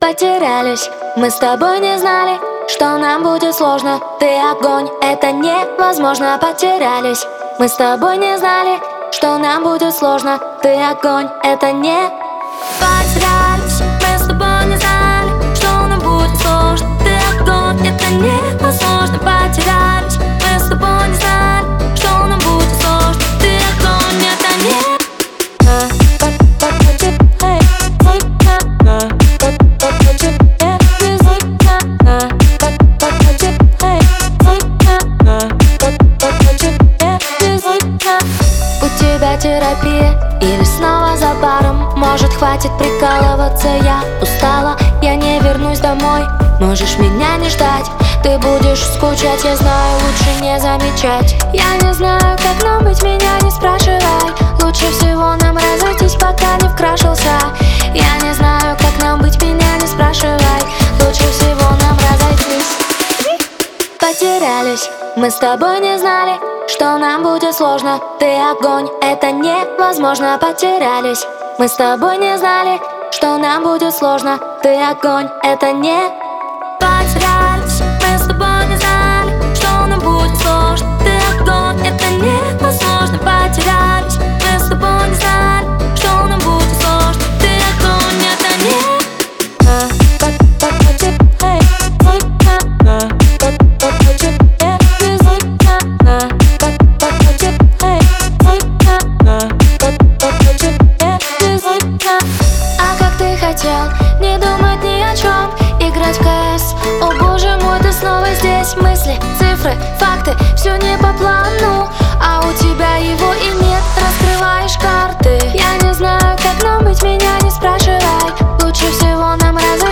это эмоциональная композиция в жанре поп-музыки